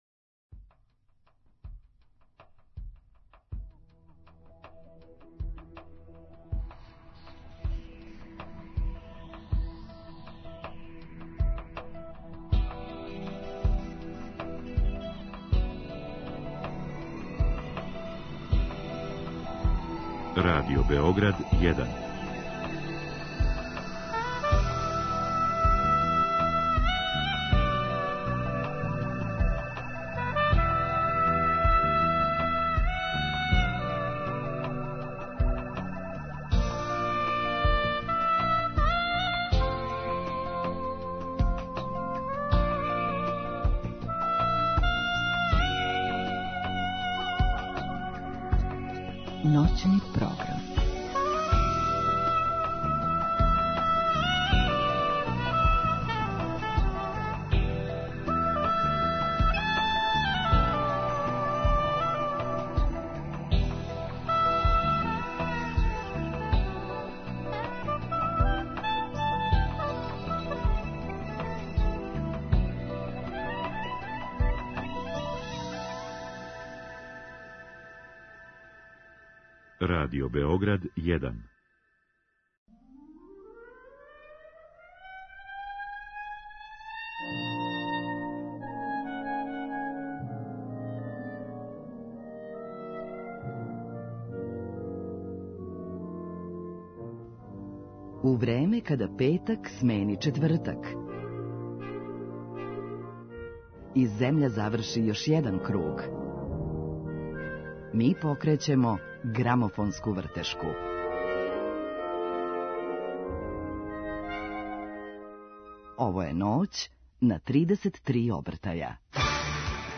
У емисији ћемо имати прилике да чујемо његове најлепше инструменталне композиције, откриће нам чиме су инспирисане и подсетићемо се највећих хитова које је потписао као аранжер. Између осталог, сазнаћемо и шта је ново у сазнањима о ванземаљским цивилизацијама.
преузми : 20.55 MB Ноћни програм Autor: Група аутора Сваке ноћи, од поноћи до четири ујутру, са слушаоцима ће бити водитељи и гости у студију, а из ноћи у ноћ разликоваће се и концепт програма, тако да ће слушаоци моћи да изаберу ноћ која највише одговара њиховом укусу, било да желе да слушају оперу или их интересује технологија.